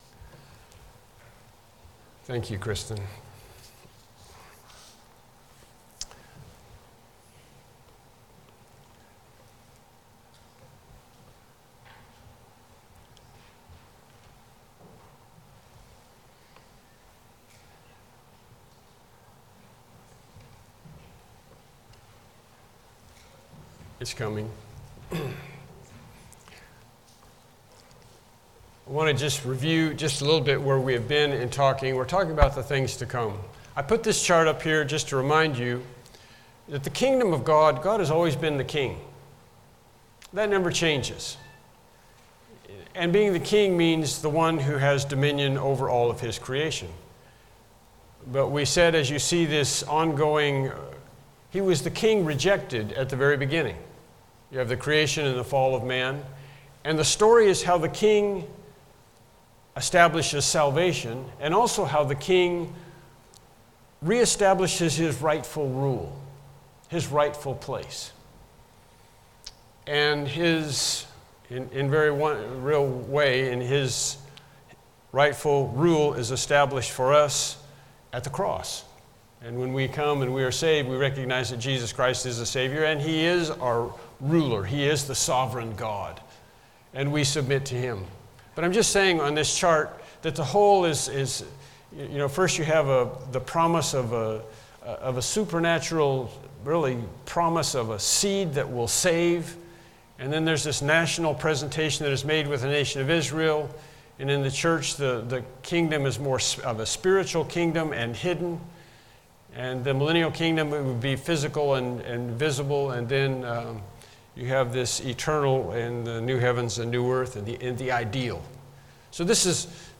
The Study of Things to Come Service Type: Evening Worship Service « Temple Destruction and the End Lesson 14